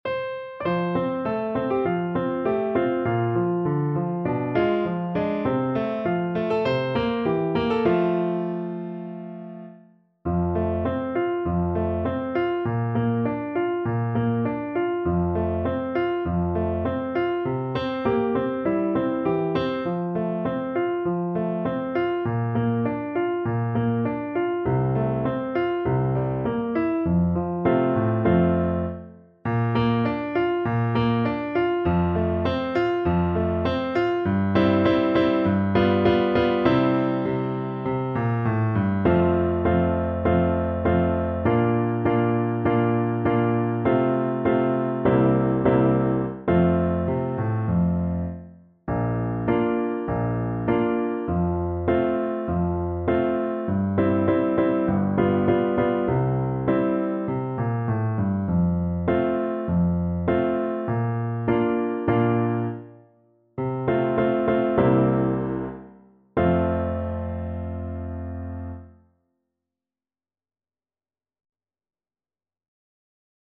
4/4 (View more 4/4 Music)
~ = 100 Moderato